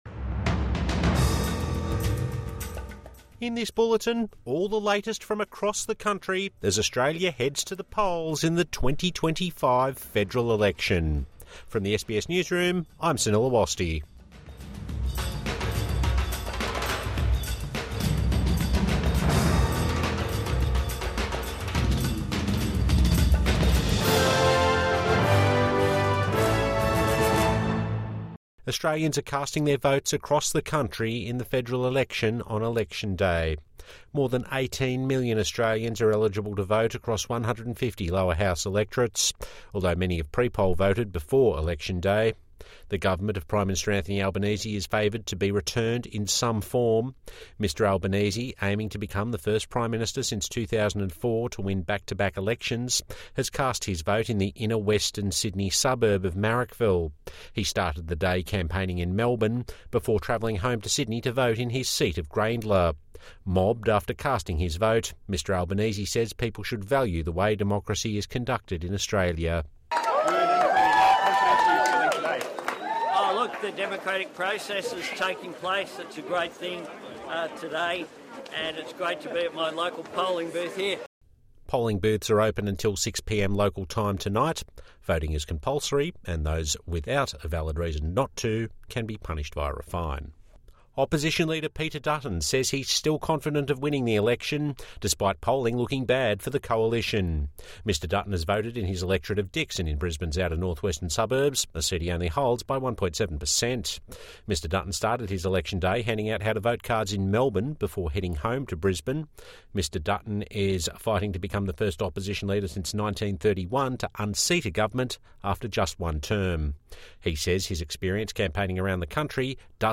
An election day news update